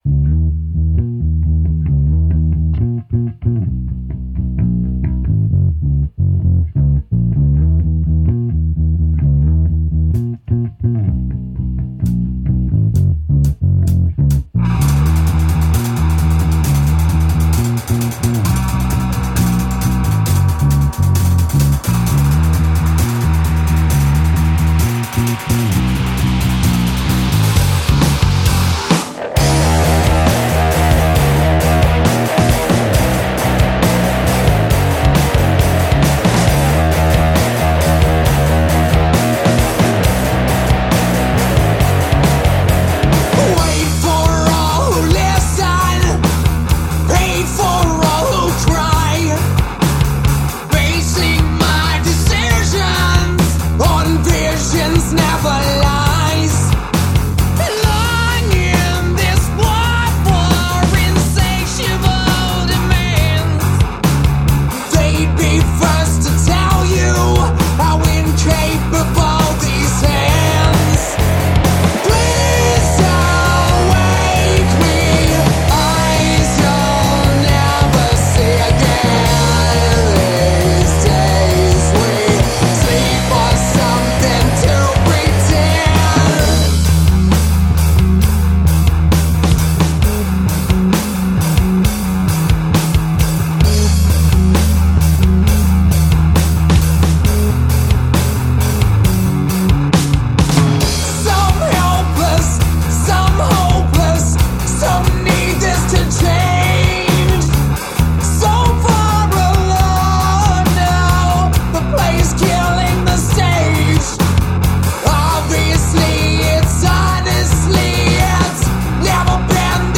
hard rock trio